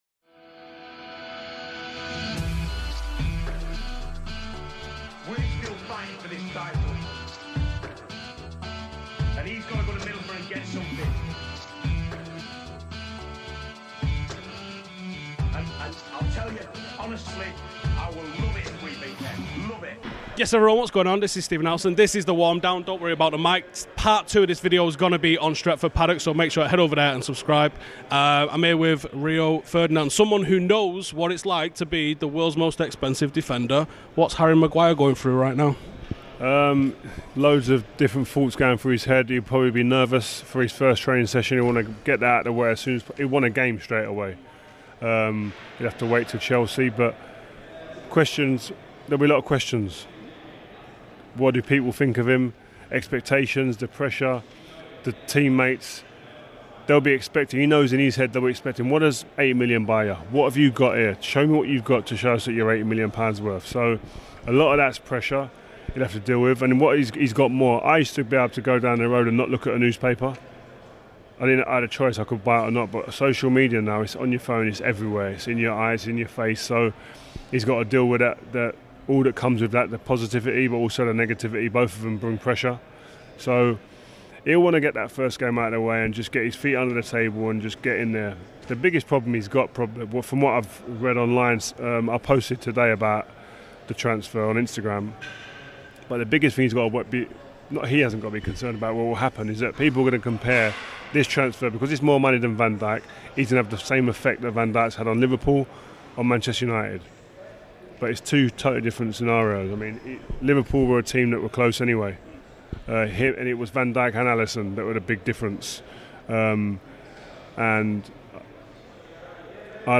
I was lucky enough to sit down for five minutes with the legend himself, Rio Ferdinand. He gives me an insight on what Maguire will be feeling having once been the most expensive defender, and the players to be looking out for this season.